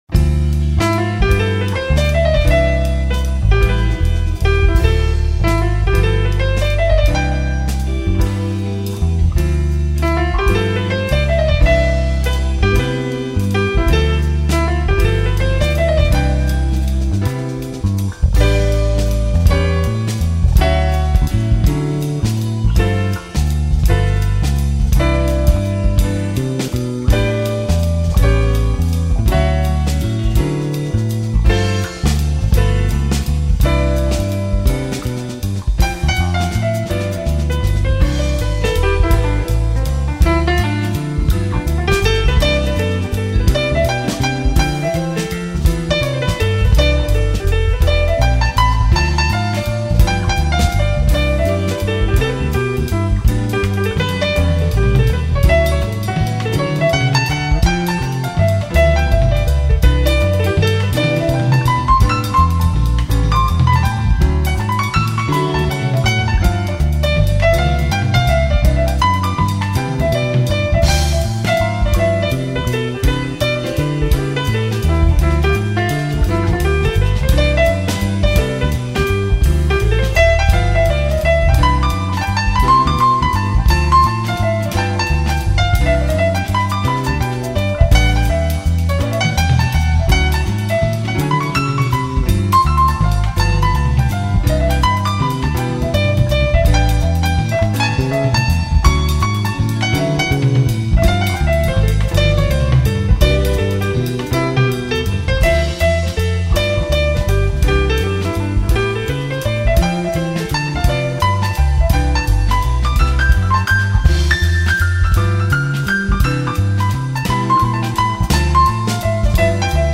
556   02:58:00   Faixa: 1    Jazz
Gravada no verão de 1996 em París.